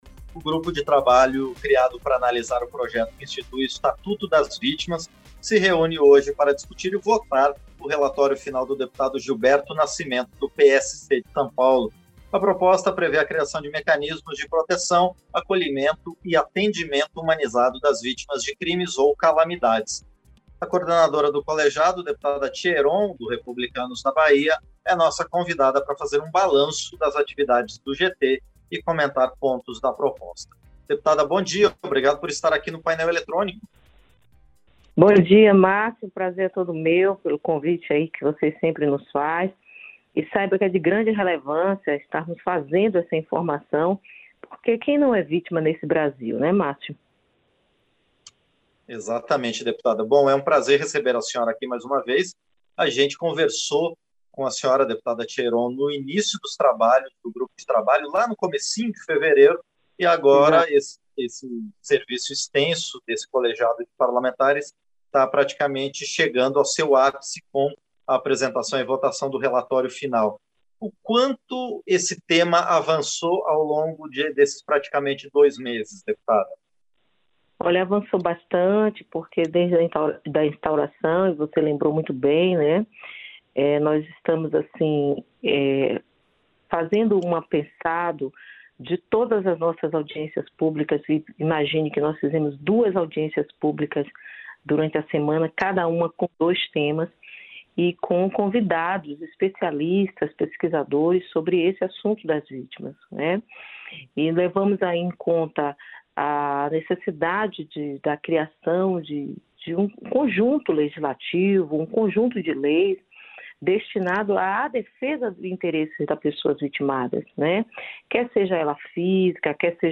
Entrevista - Dep. Tia Eron (Republicanos - BA)